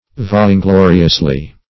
vaingloriously.mp3